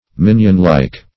Meaning of minionlike. minionlike synonyms, pronunciation, spelling and more from Free Dictionary.
Search Result for " minionlike" : The Collaborative International Dictionary of English v.0.48: Minionlike \Min"ion*like`\, Minionly \Min"ion*ly\, a. & adv. Like a minion; daintily.